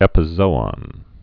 (ĕpĭ-zōŏn, -ən)